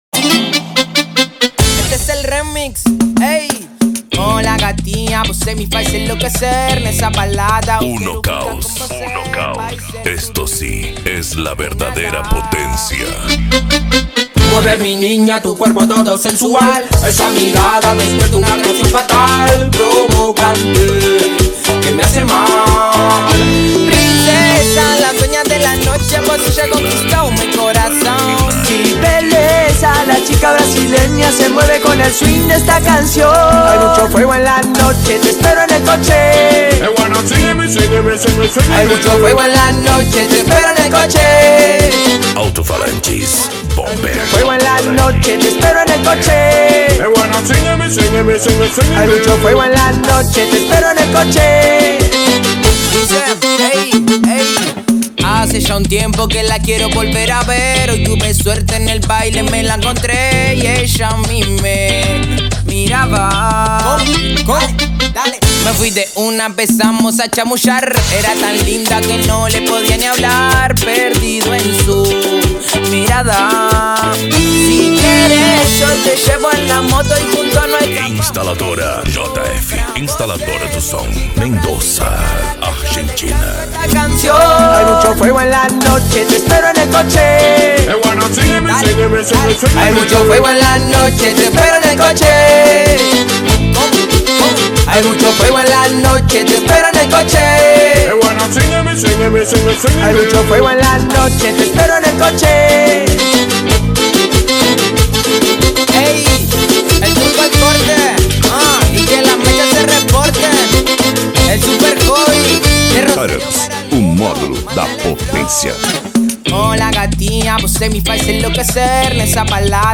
Bass
Musica Electronica
Remix